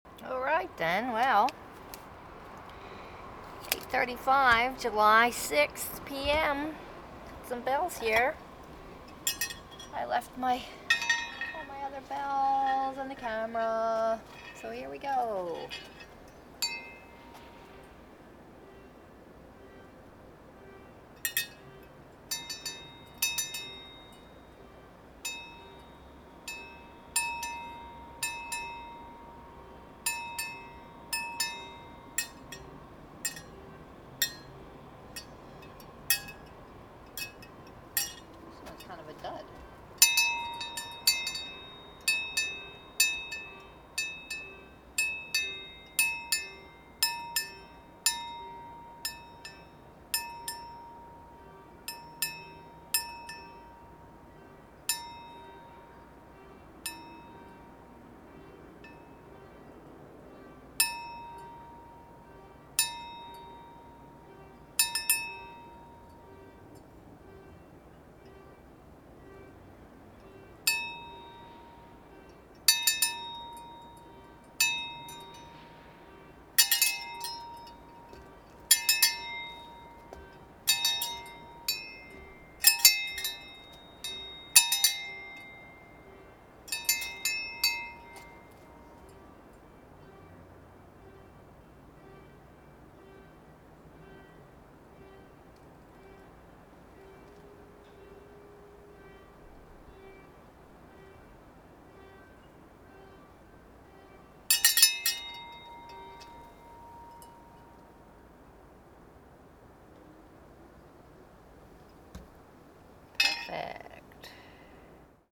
When I went out to ring this evening, someone else was already making some noise, so I just joined in with my cowbells.